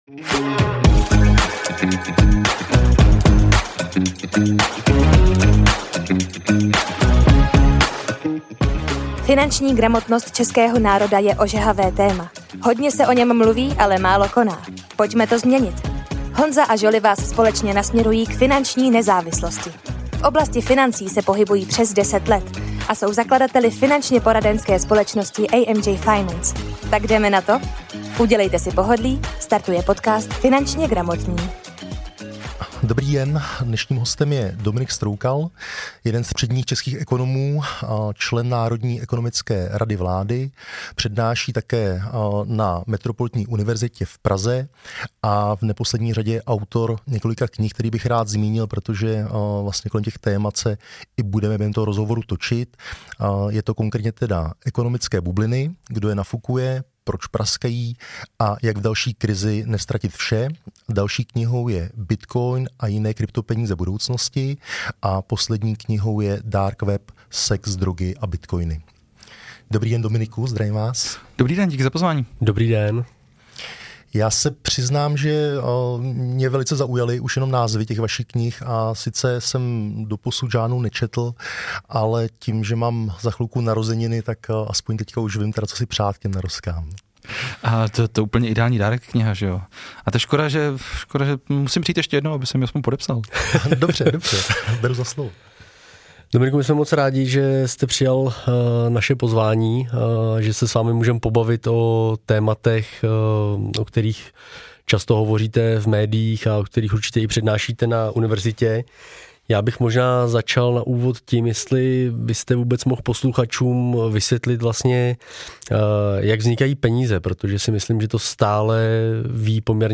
Ekonomické bubliny - Kdo je nafukuje, proč praskají a jak v další krizi neztratit vše, to je název knihy Dominika Stroukala a jedno z témat 1. části rozhovoru.